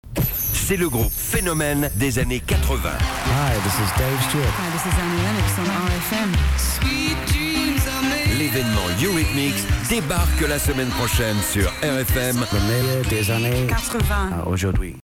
Radio advert 1 (338 Kb)
RFM-advert-short.mp3